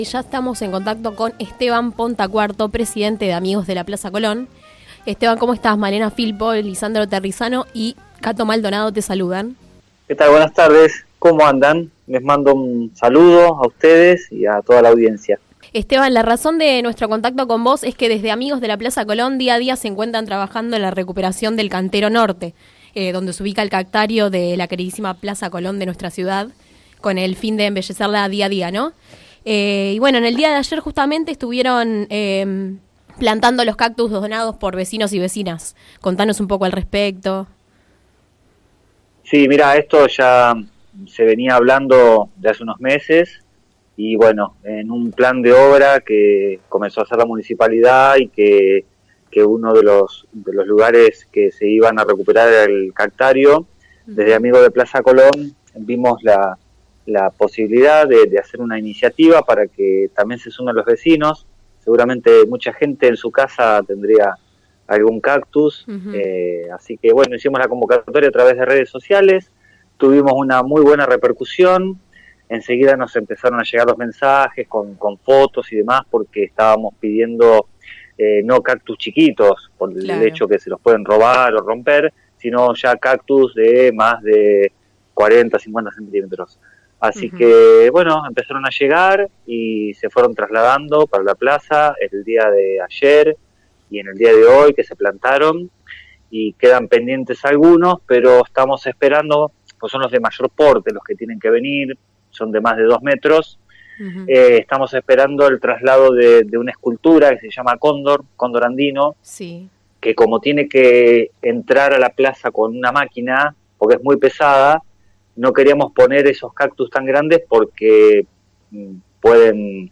En declaraciones al programa “Sobre las cartas la mesa” de FM Líder 97.7